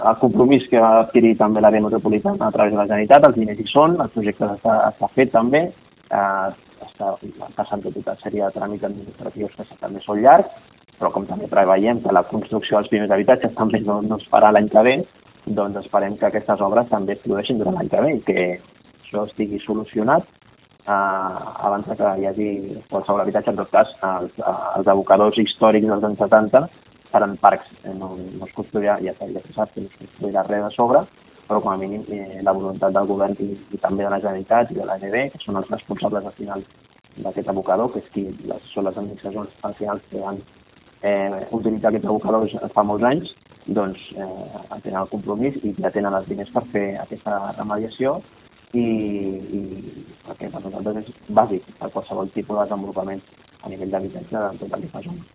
Declaracions de Carlos Cordon